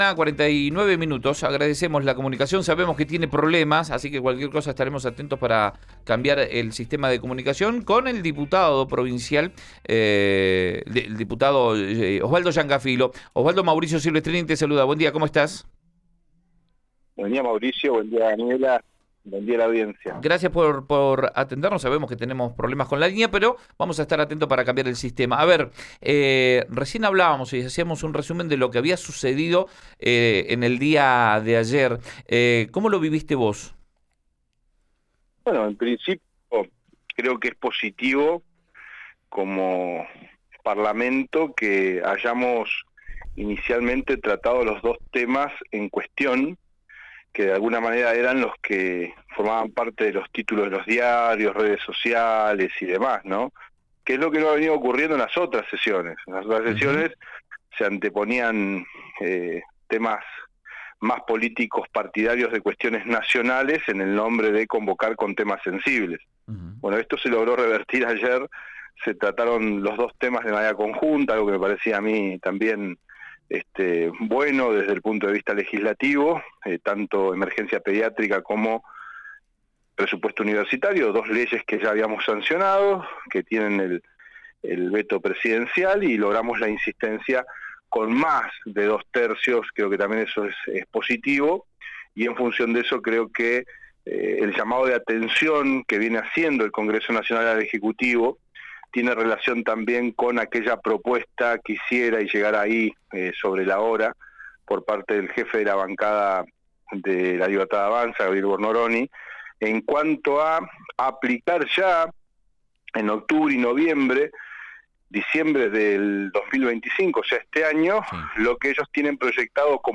Escuchá al diputado nacional Osvaldo Llancafilo, en Rn Radio: